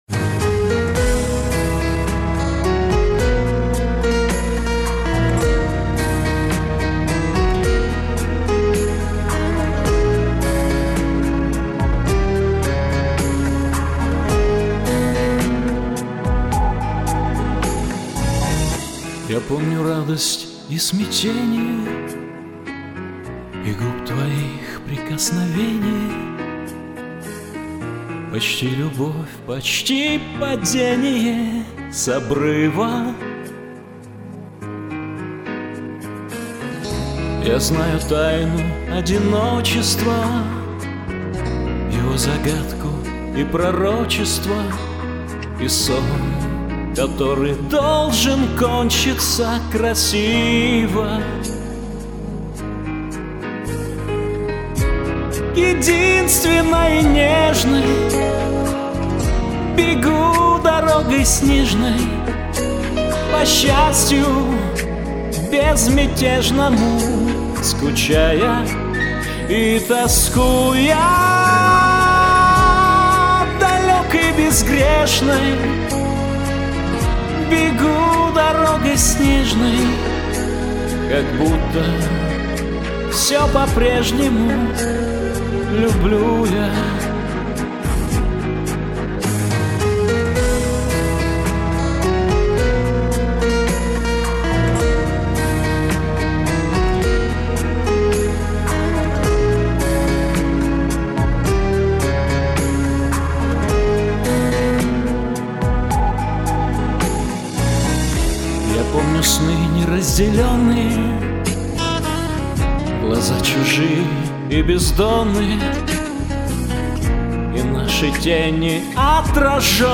в мужском формате песня